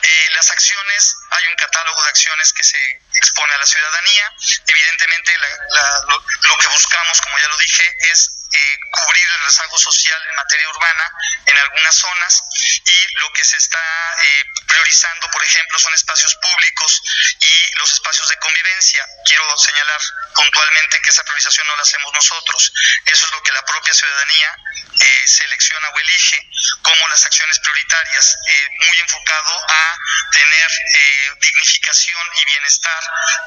En conferencia de prensa, con la presencia de la alcaldesa Claudia Rivera Vivanco, el funcionario municipal destacó que los rubros que se tomarán en cuenta a petición de las y los ciudadanos son el tema relacionado con la salud, así como el de gimnasios al aire libre, dignificaron de parques; acciones que se han priorizado en las asambleas que se han sostenido.